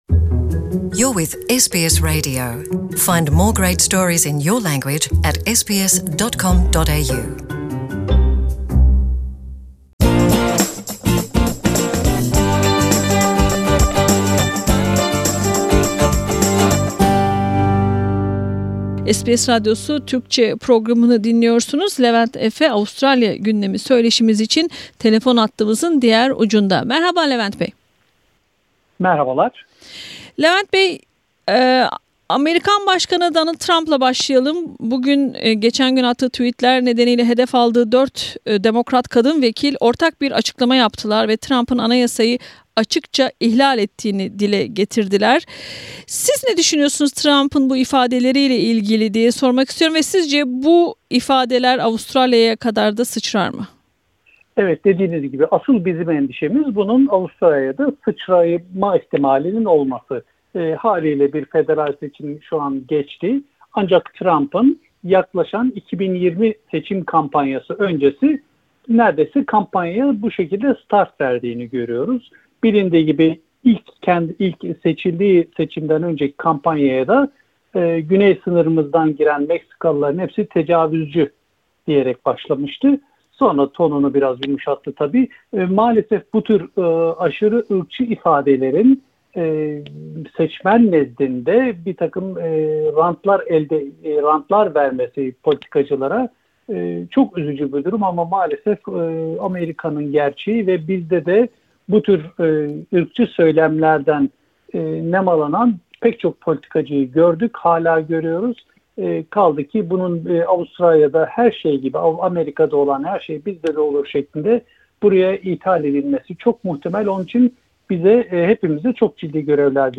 Yorumcu